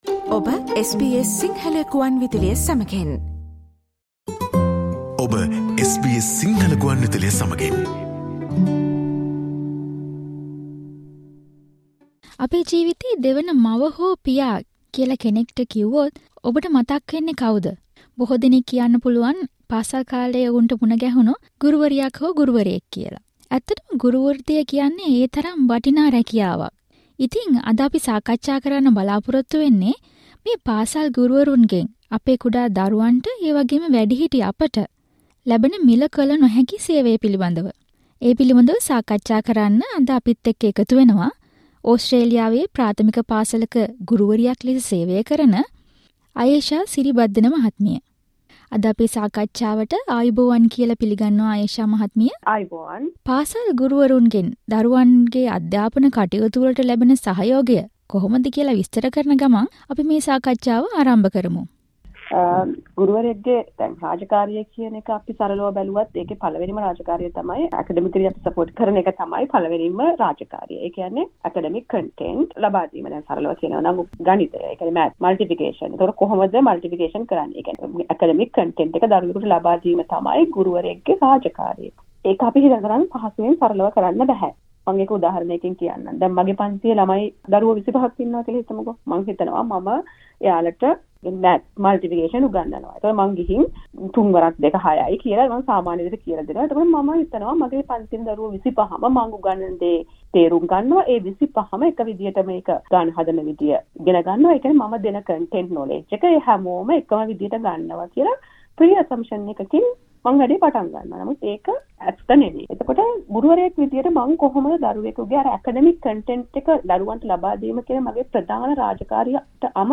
Listen to SBS Sinhala discussion on school-based support for children's education and well-being.